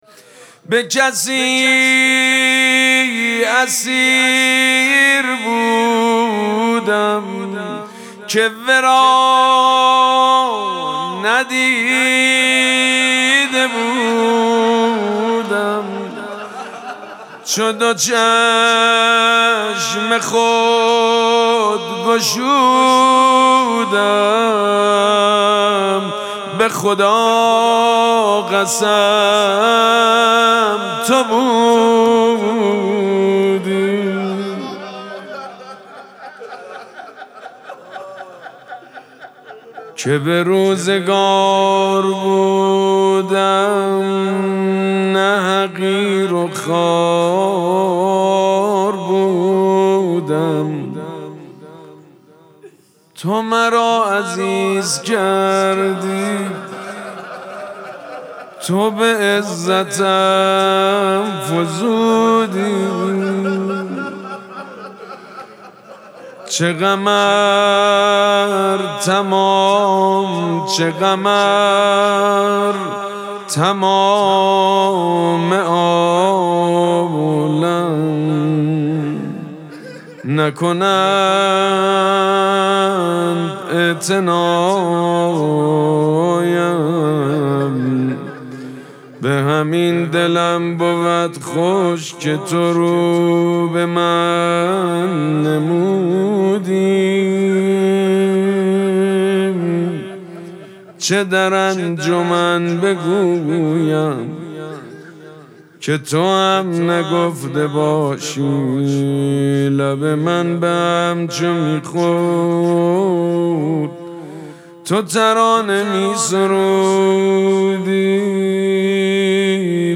مراسم مناجات شب چهارم ماه مبارک رمضان
حسینیه ریحانه الحسین سلام الله علیها
مناجات